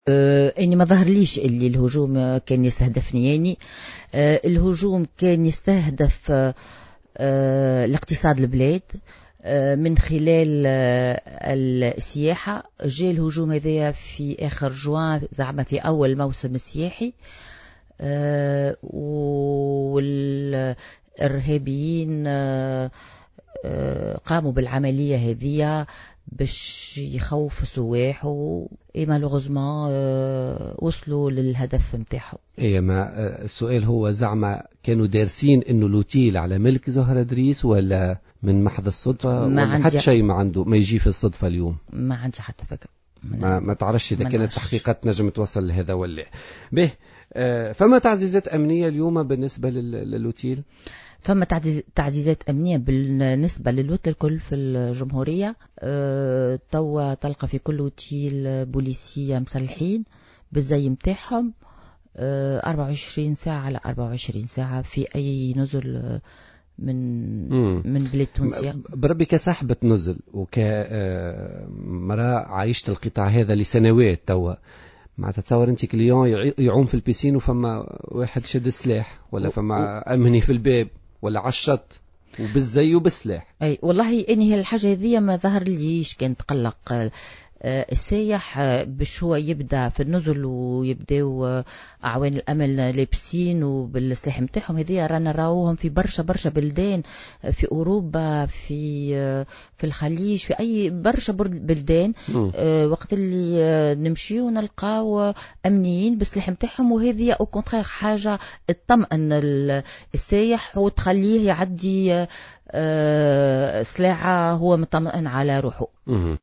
قالت زهرة إدريس، النائب بمجلس الشعب وصاحبة نزل "امبريال مرحبا" ضيفة برنامج "بوليتيكا" اليوم الثلاثاء، إنها لم تكن مستهدفة و إن الهدف من هذه العملية الإرهابية التي جدّت مؤخرا بالنزل هو ضرب أحد القطاعات الحيوية للاقتصاد التونسي.